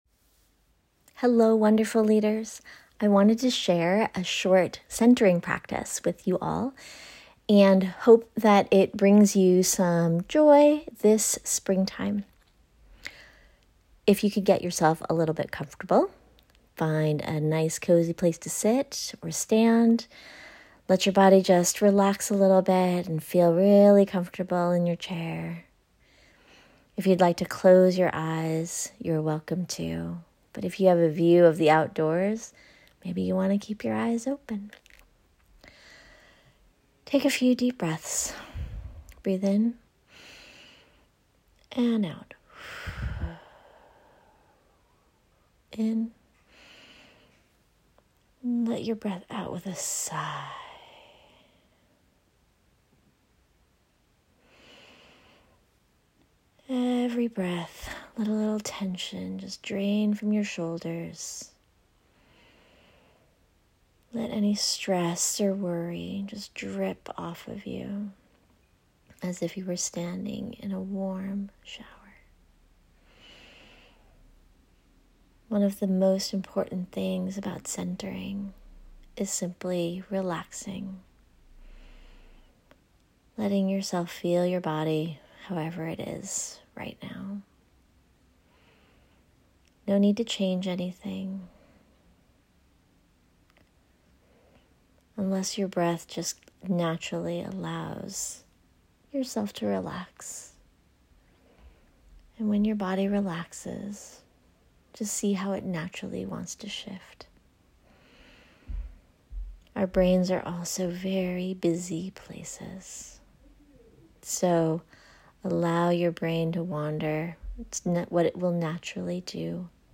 7 minute centering meditation I recorded for you to try. Try it as a break in your busy day when you need to shift away from stress and exhaustion towards spaciousness.